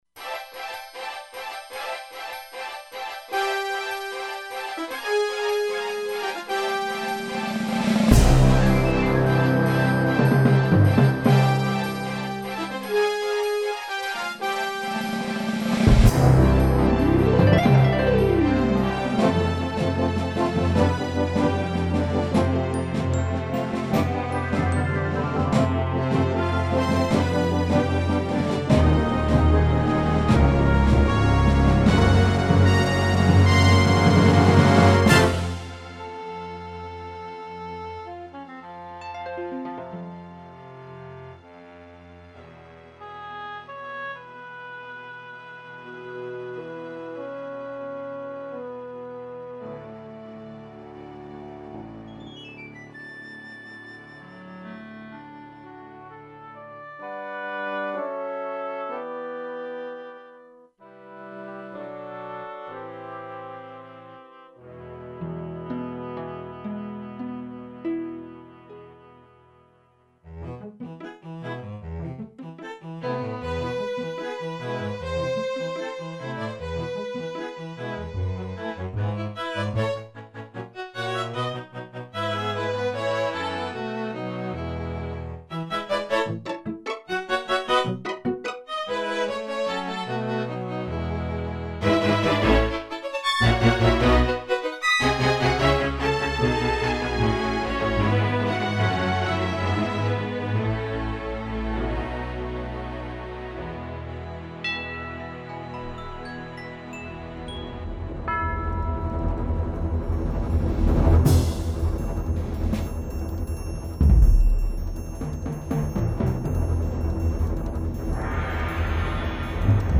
- Plus Orchetral extra rom adds 4 MB of orchestral sounds (same found on PROTEUS 2 module)
HEAR orchestral SOUND XP